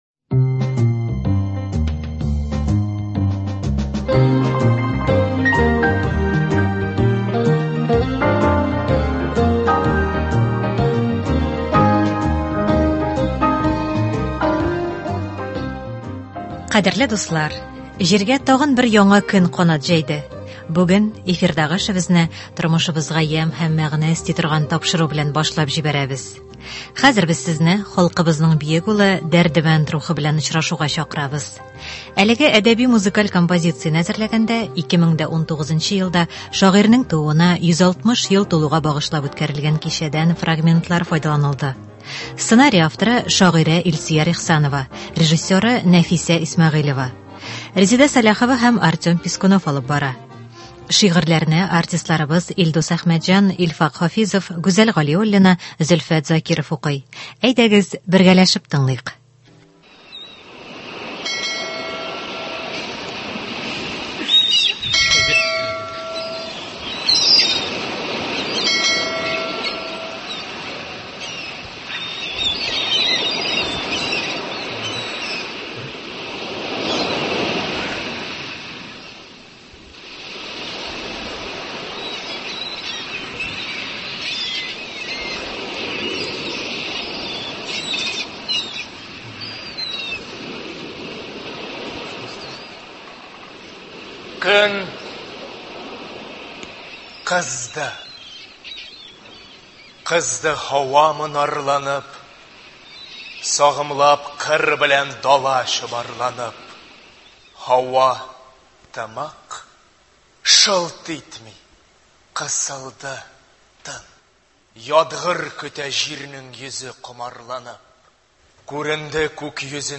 Дәрдемәнд әсәрләреннән әдәби-музыкаль композиция.
Хәзер без сезне халкыбызның бөек улы Дәрдемәнд рухы белән очрашуга чакырабыз. Әлеге әдәби-музыкаль композицияне әзерләгәндә 2019 елда шагыйрьнең тууына 160 ел тулуга багышлап үткәрелгән кичәдән фрагментлар файдаланылды.